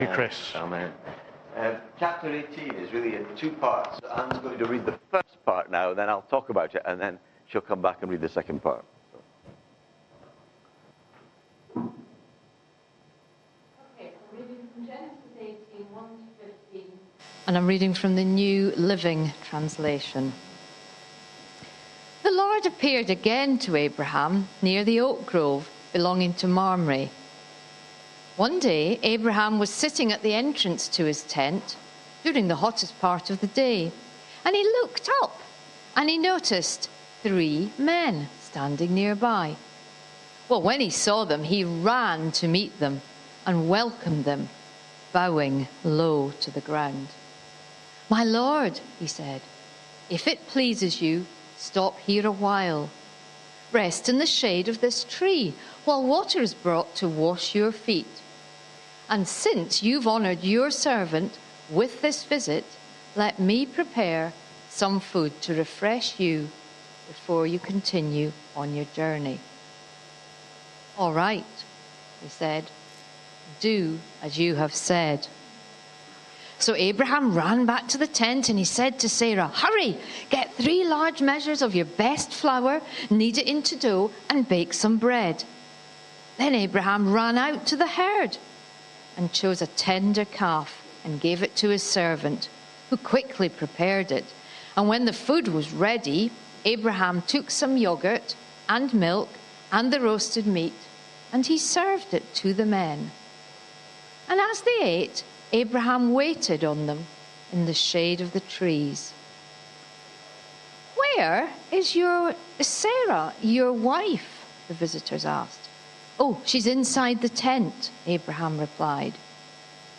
This sermon explores the story of Abraham and Sarah from Genesis 18, focusing on the themes of faith, prayer, and God's character.